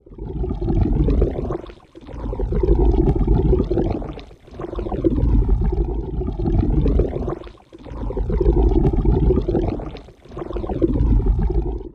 water.ogg